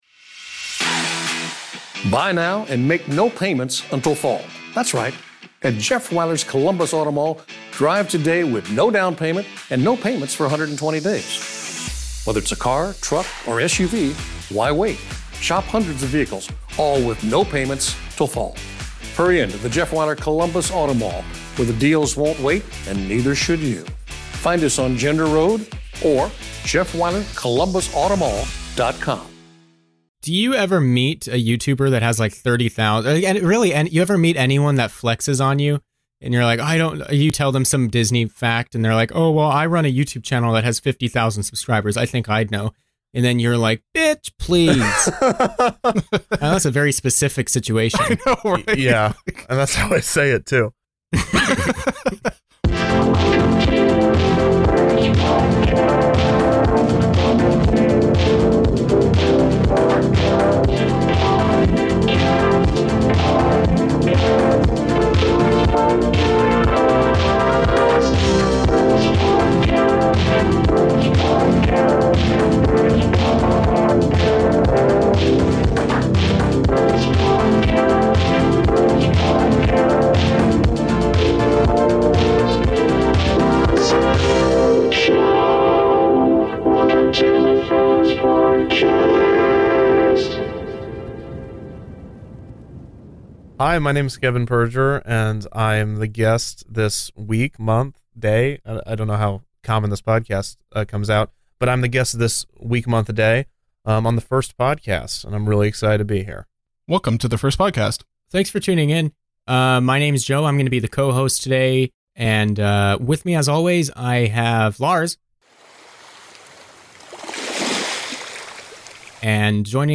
This episode was recorded live on our Discord.